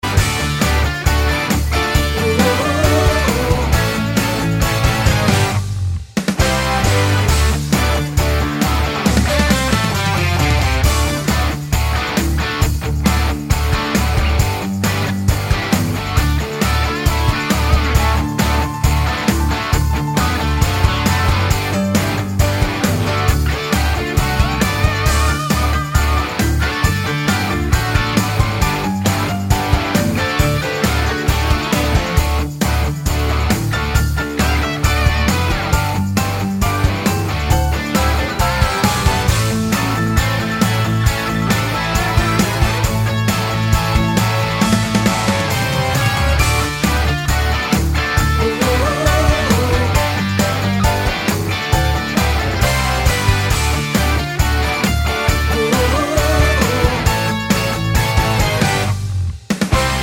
no Backing Vocals Soundtracks 2:56 Buy £1.50